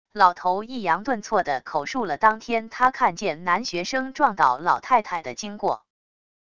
老头抑扬顿挫地口述了当天他看见男学生撞倒老太太的经过wav音频